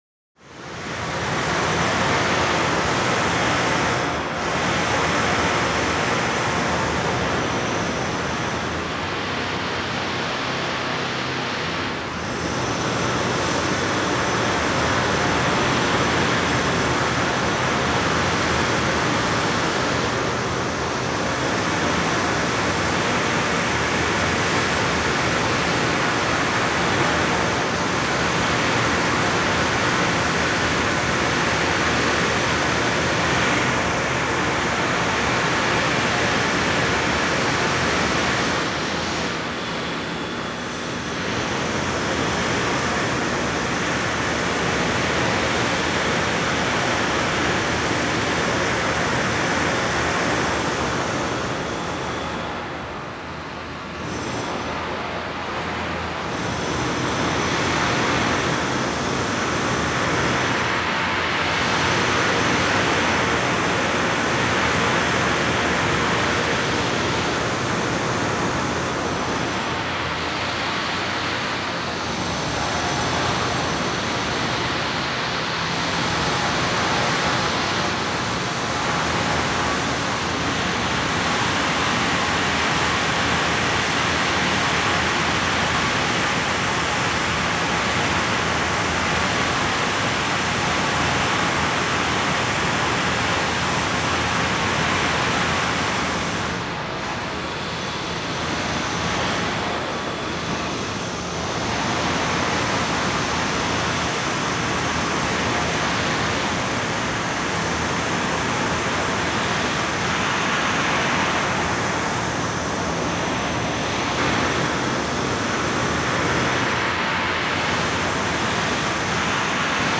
Construction, Hammer Sound
ambience
Construction, Hammer